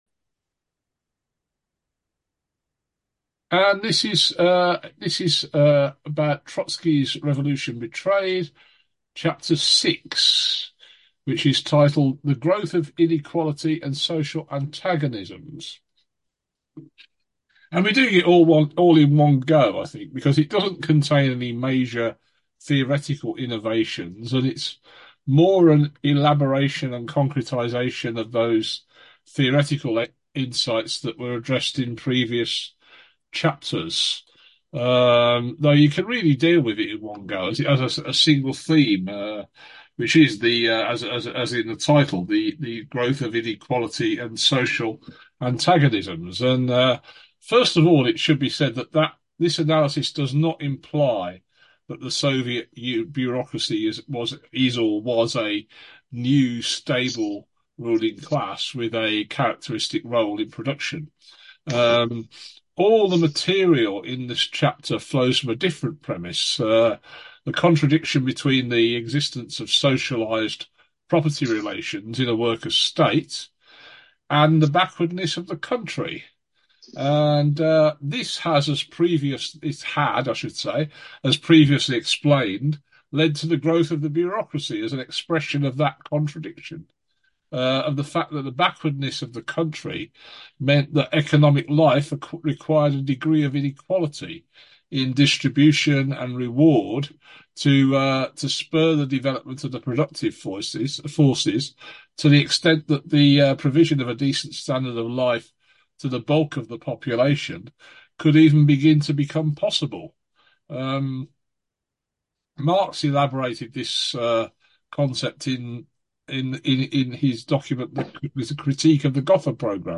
The notes for this presentation (24th March) are now available to read, and the presentation and discussion are also available to listen to as a podcast.